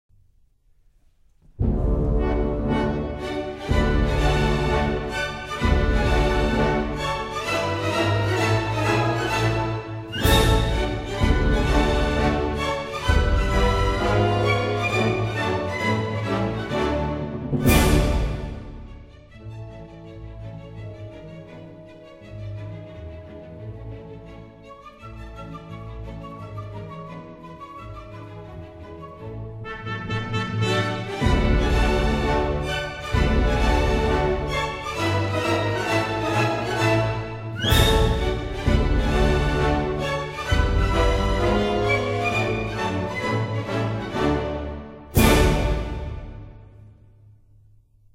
Pesante
沉重地<00:48>